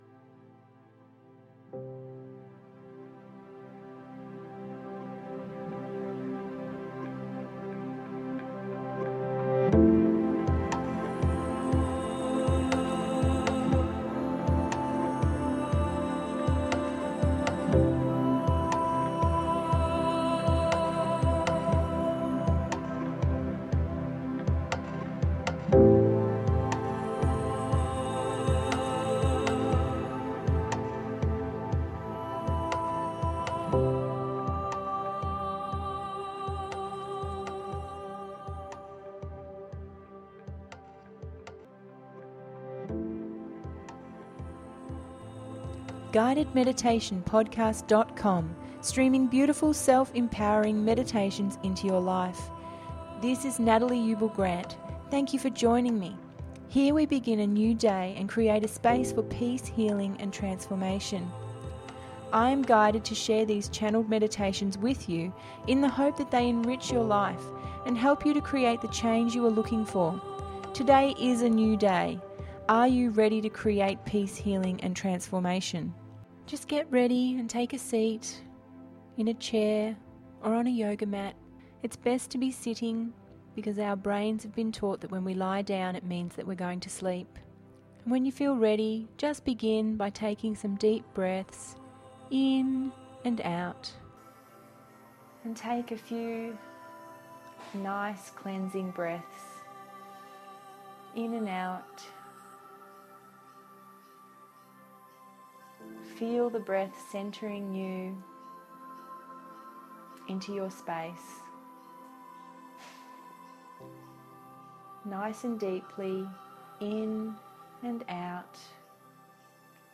Grounding…028 – GUIDED MEDITATION PODCAST